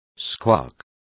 Complete with pronunciation of the translation of squawk.